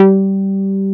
R MOOG G4MF.wav